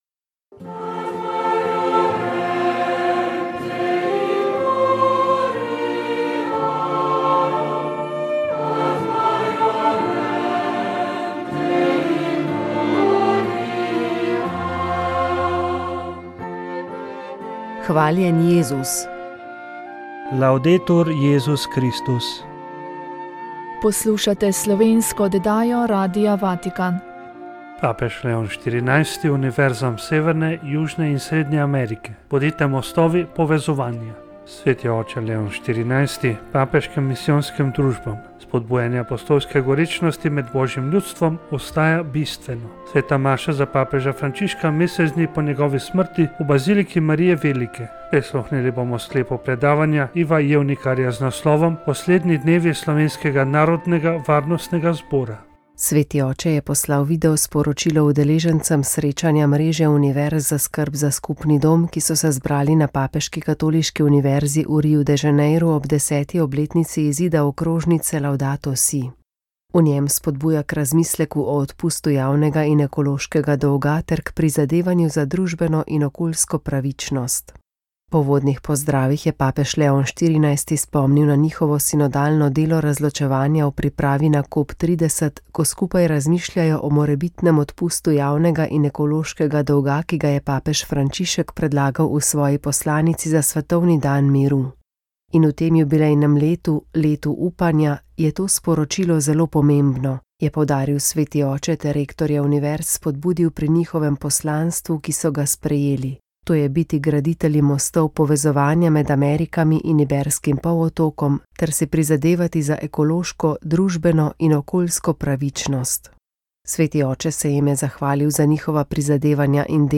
komentar